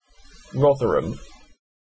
Rotherham (/ˈrɒðərəm/
En-uk-Rotherham.ogg.mp3